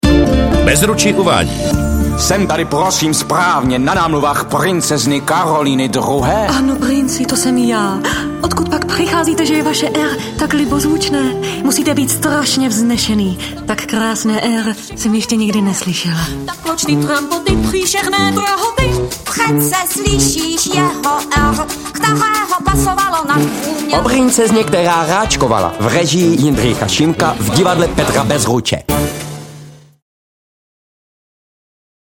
Audio-upoutávka na pohádku O princeezně, která ráčkovala